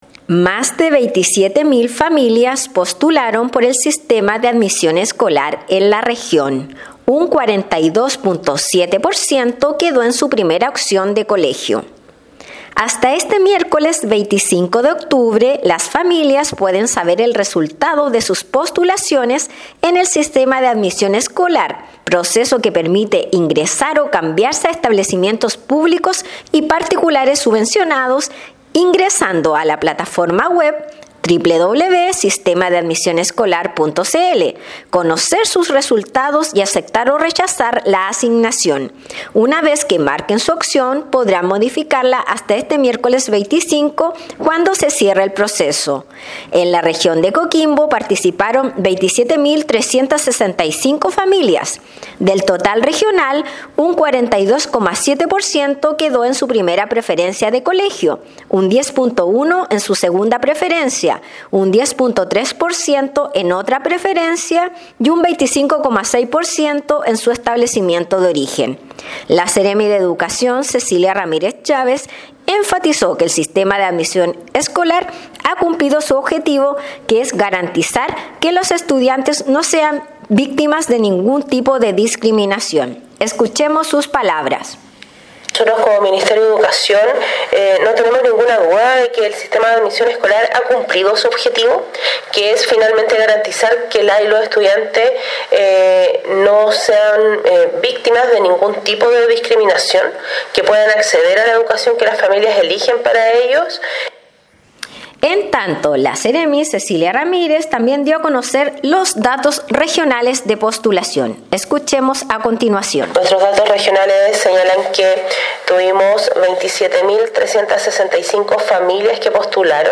Despacho-Radial_-Mas-de-27-mil-familias-postularon-por-el-Sistema-de-Admision-Escolar-en-la-region_.mp3